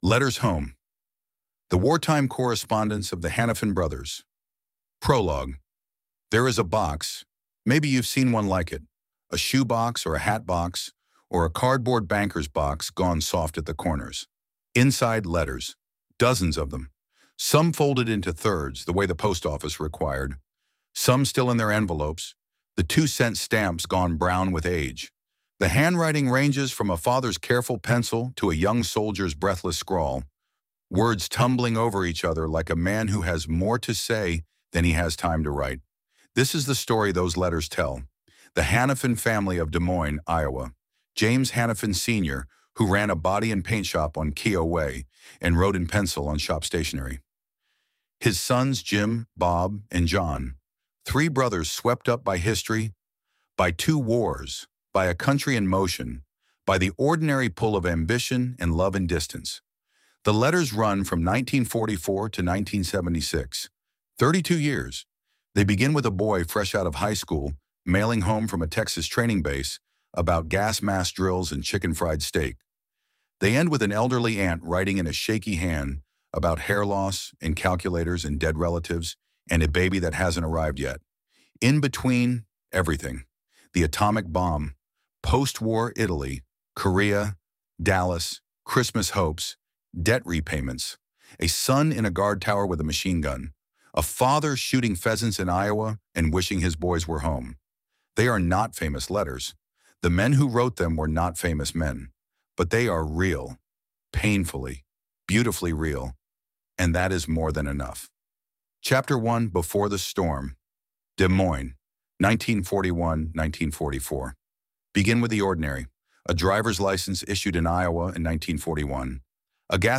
Letters_Home_ElevenLabs.mp3